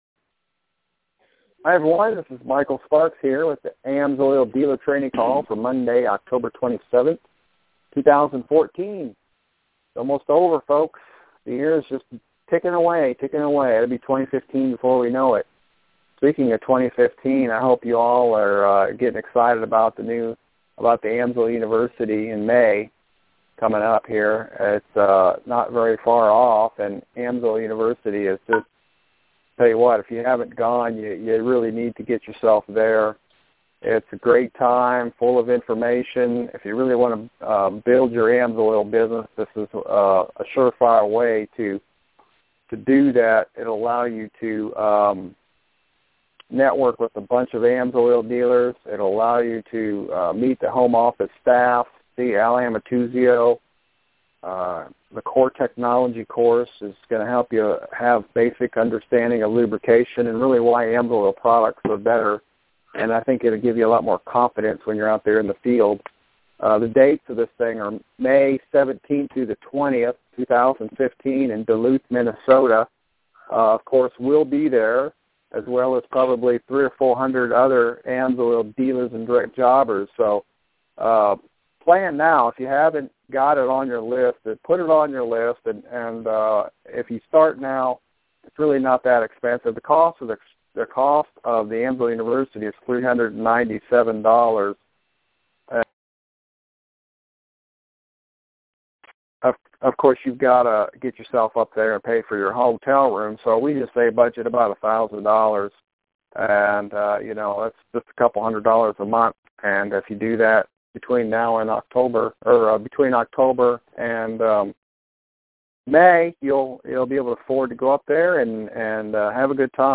Sparks Team AMSOIL Dealer Training Call | October 27th, 2014